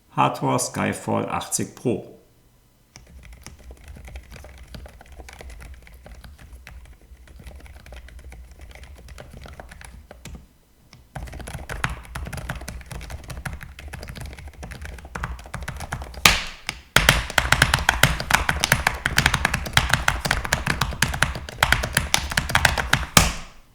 Tonal agiert sie tiefer als be quiets Light Mount (Test), sie erzeugt sattere, dafür nicht ganz so klare Anschläge.
Man muss klar sagen: Die Hator Skyfall Pro ist eine sehr leise Tastatur mit angenehmen, massentauglichen Klangbild.
Hator Skyfall 80 Pro Wireless (Hator Aurum Vanilla Silent)